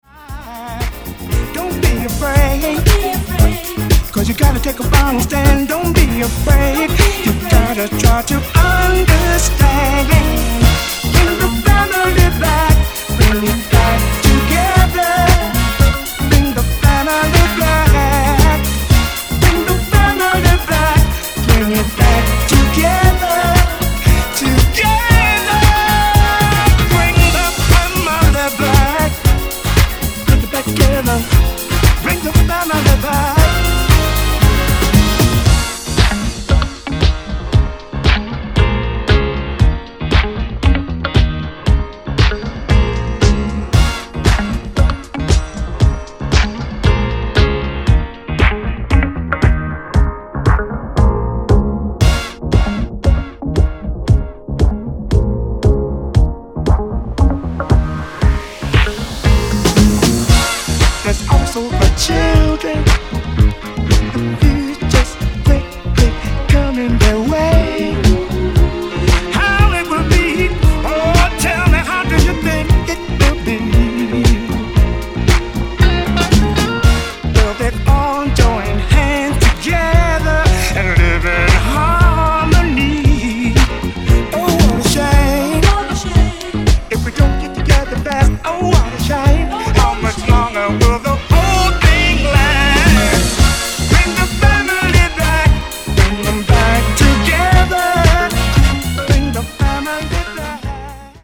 リエディット